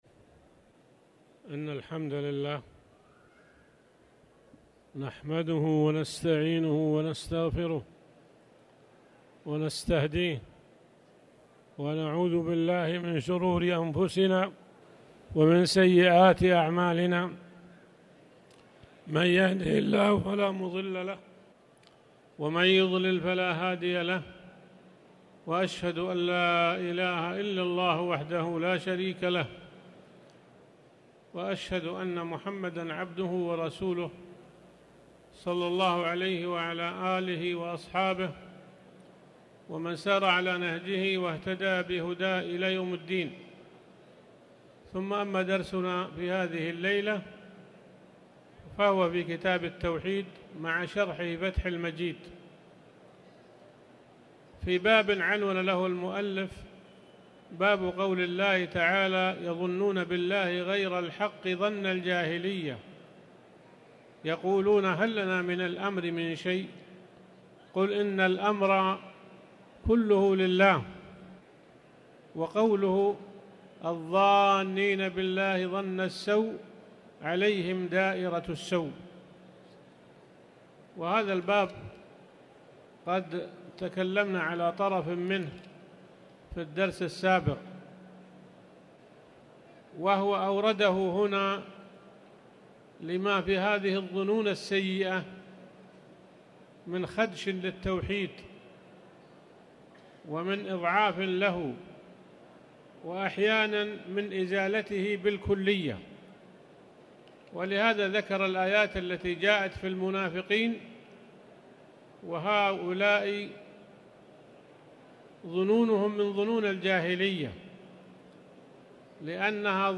تاريخ النشر ٥ ربيع الأول ١٤٤٠ هـ المكان: المسجد الحرام الشيخ